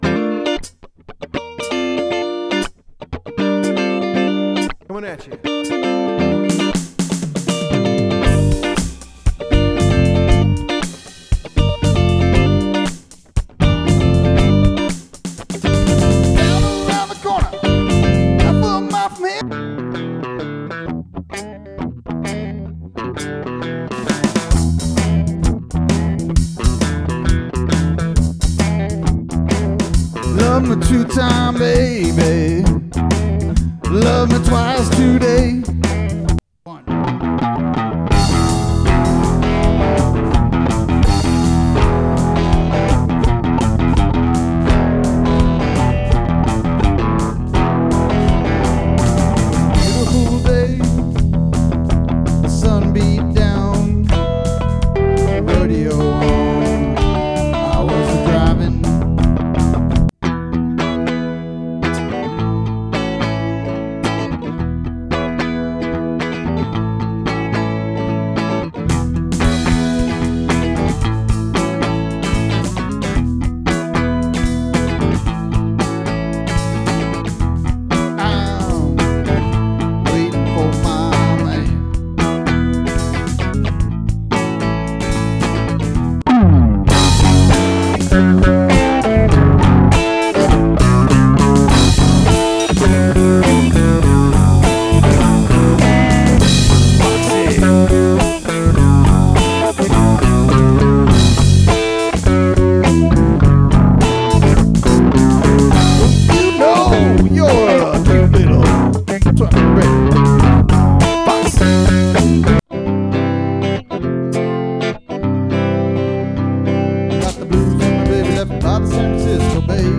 ~ Good time Classic Rock & Blues for all occasions ~
Classic rock, original jams, & hoppin' blues
shreds guitar, blows mean sax, and brings the vocals home.